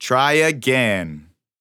8 bits Elements
Voices Expressions Demo
TryAgain_1.wav